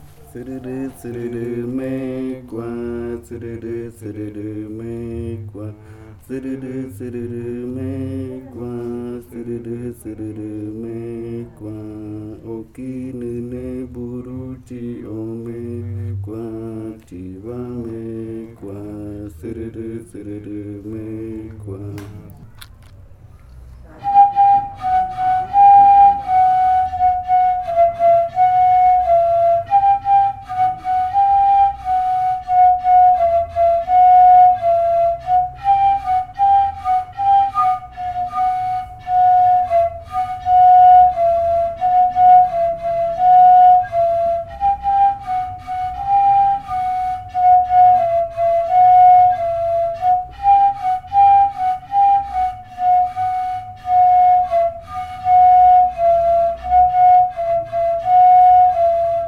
Leticia, Amazonas, (Colombia)
Grupo de danza Kaɨ Komuiya Uai
Canto Zɨrɨrɨ e interpretación del canto en pares de reribakui.
Zɨrɨrɨ chant and performance of the chant in reribakui flutes.
Flautas de Pan y cantos de fakariya del grupo Kaɨ Komuiya Uai